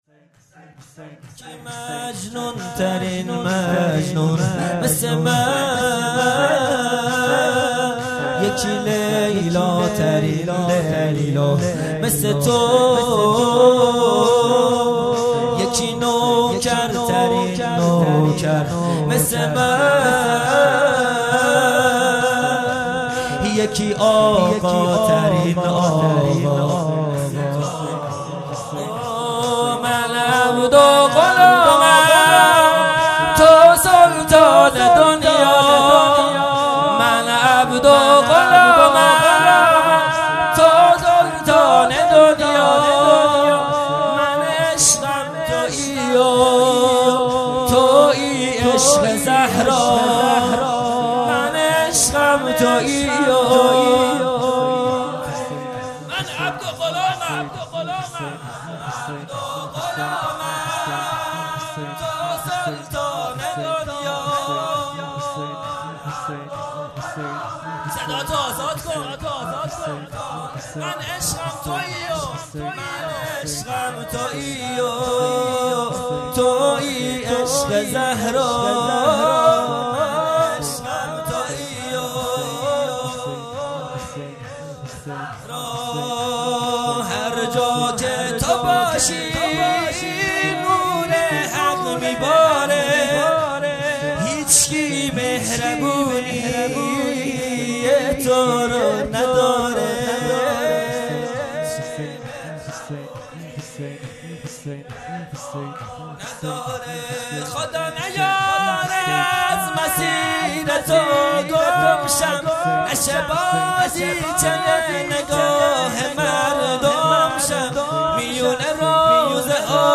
خیمه گاه - هیئت بچه های فاطمه (س) - شور | مجنون ترین مجنون مثل من
جلسه هفتگی هیئت به مناسبت شهادت امام صادق(ع)